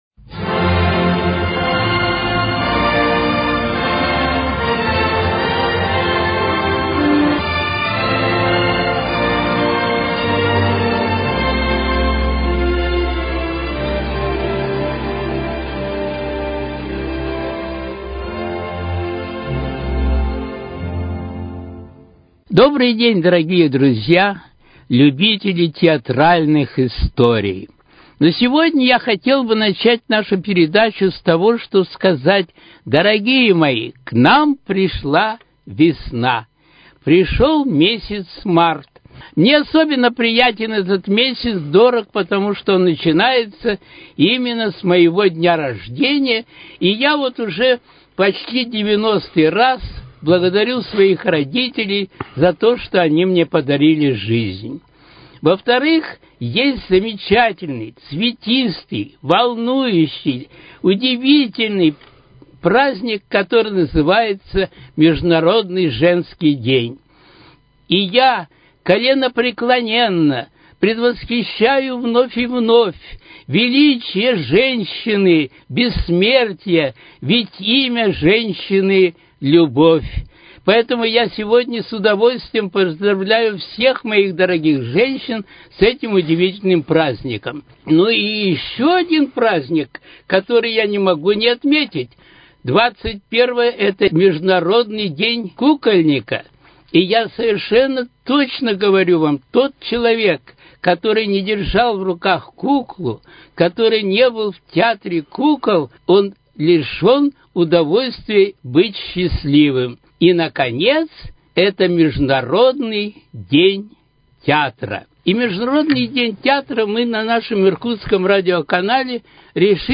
Театральные истории: Беседа